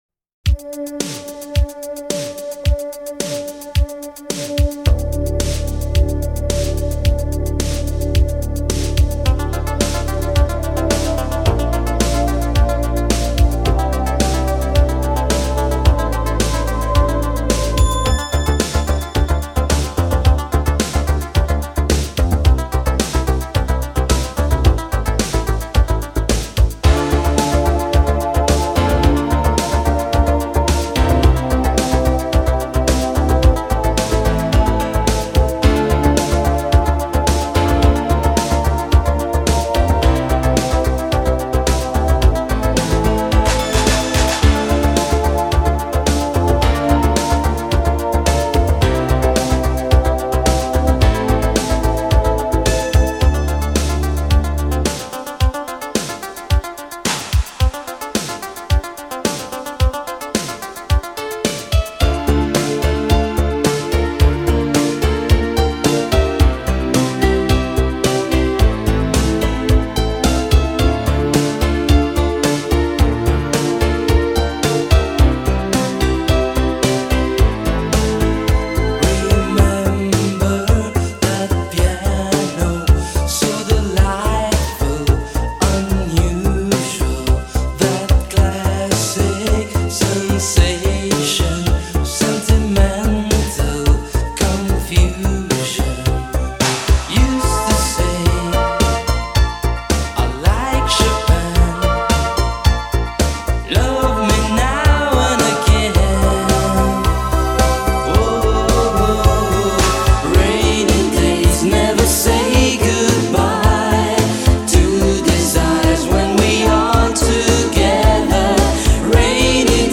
Такой прикольный припев, зажигающий, на весь день))